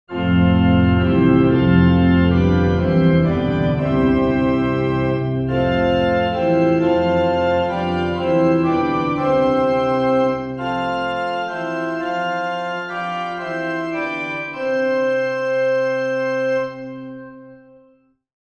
For comparison, the RT-vs-frequency plot of the model with materials adjusted for as nearly a flat RT contour as possible is shown in Figure 3.
4. Organ “flat” RT
organ-flat-RT-1.wav